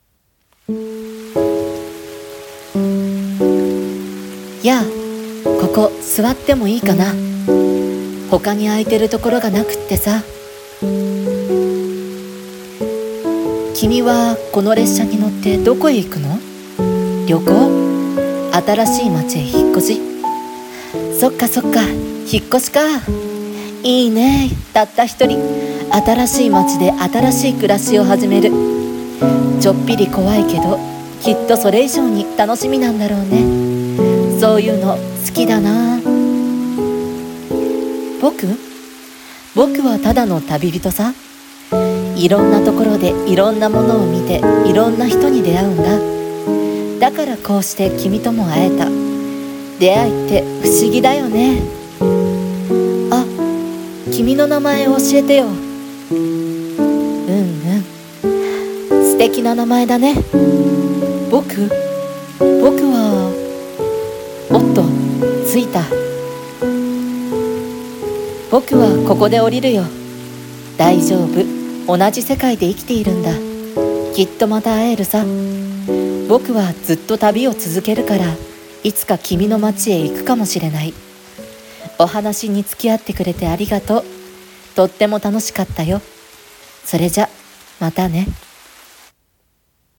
声劇「みしらぬネコ